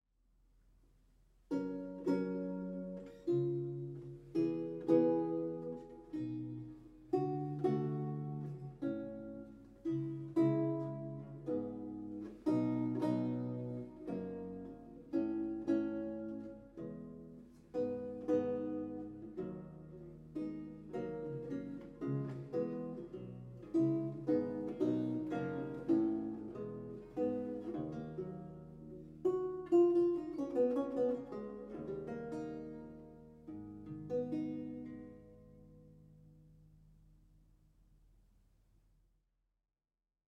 Audio recording of a lute piece
a 16th century lute music piece originally notated in lute tablature